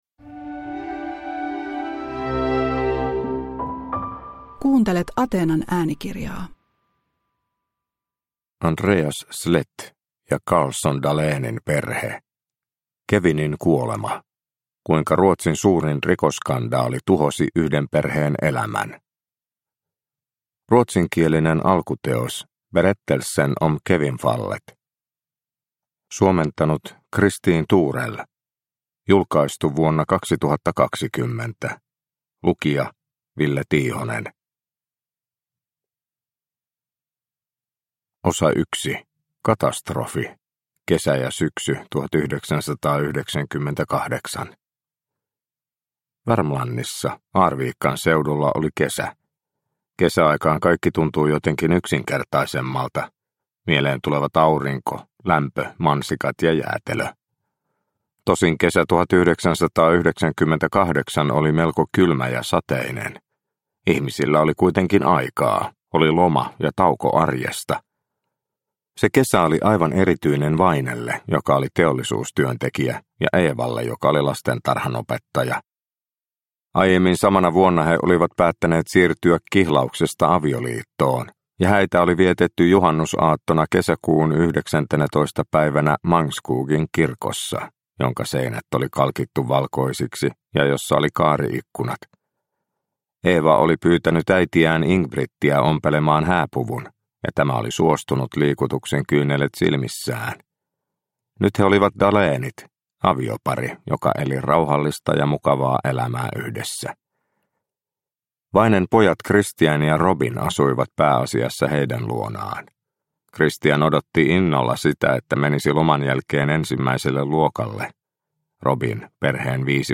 Kevinin kuolema – Ljudbok – Laddas ner